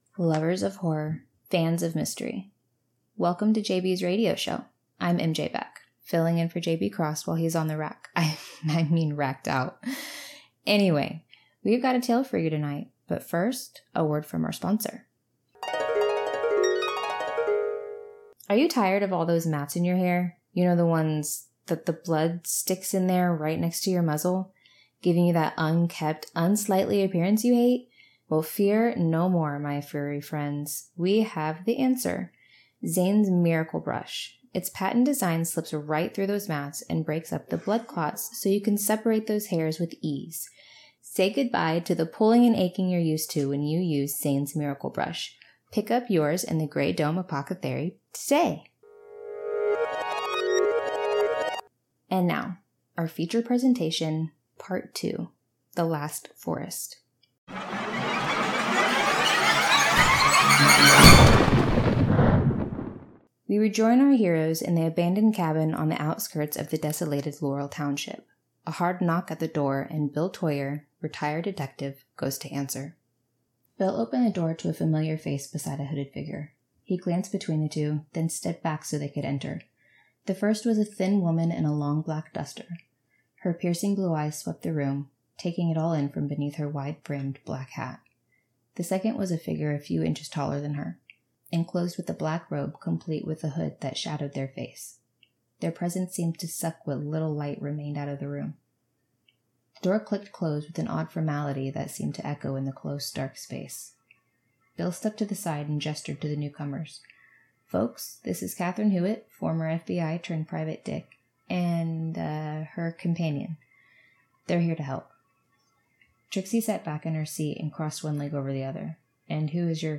Format: Audio Book
Writing: Scripted Voices: Solo
Soundscape: Music